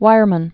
(wīrmən)